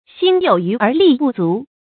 xīn yǒu yú ér lì bù zú
心有余而力不足发音